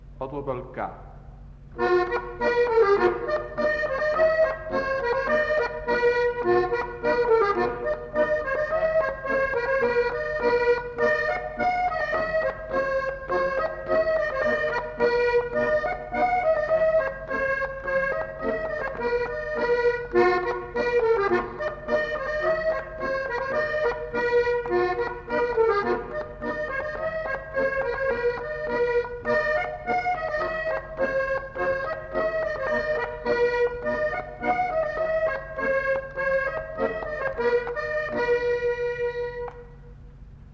enquêtes sonores
Polka